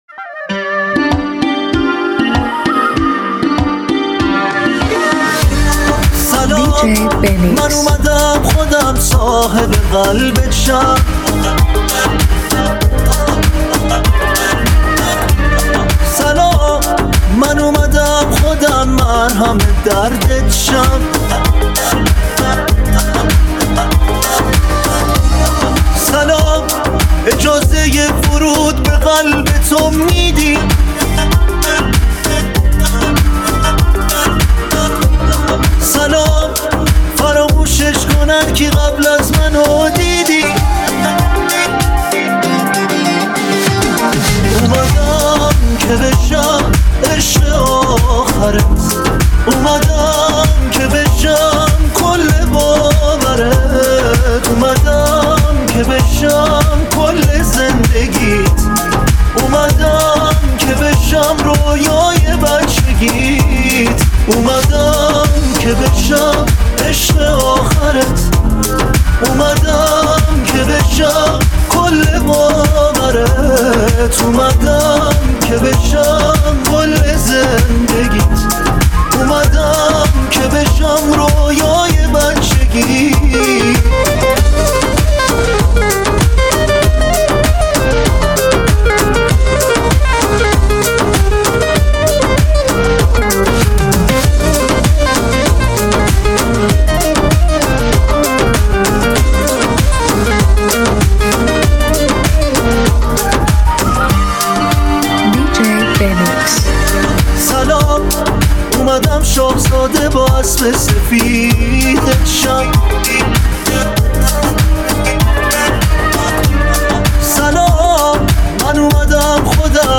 تجربه‌ای پرانرژی و متفاوت که شما را غرق در هیجان می‌کند.